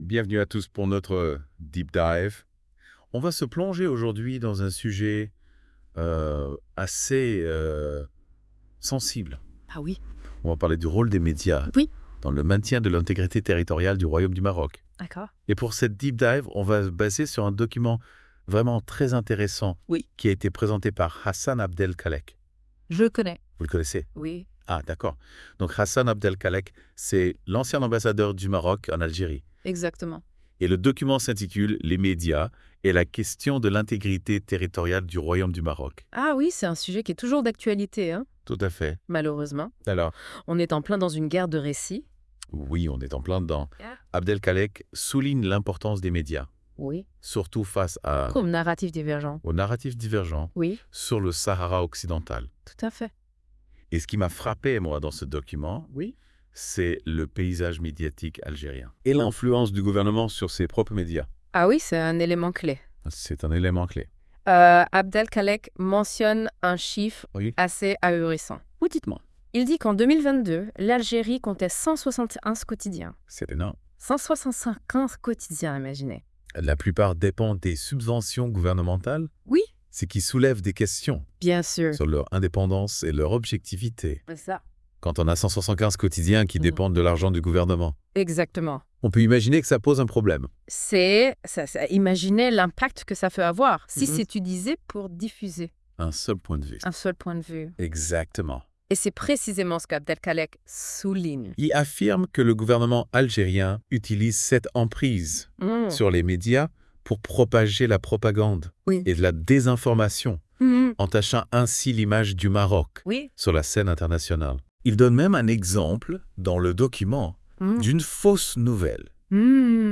Débat à écouter